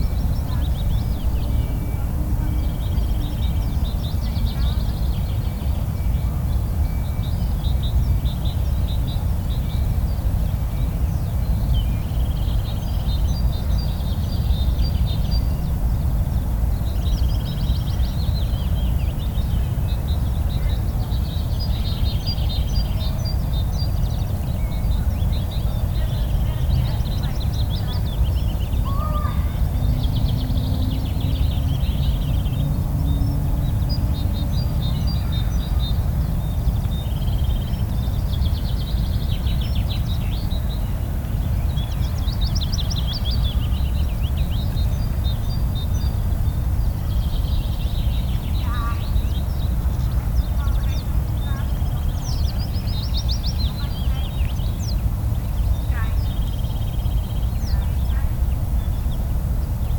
drifting sand area Loonse en Drunense Duinen Netherlands 1040 am 250404_1067
Category 🌿 Nature
ambiance ambience ambient atmospheric background-sound birds calm drifting-sand-dunes sound effect free sound royalty free Nature